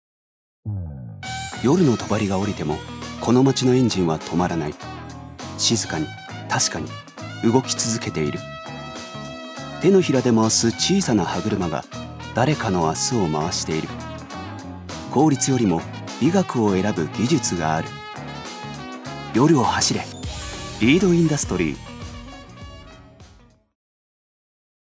所属：男性タレント
ナレーション５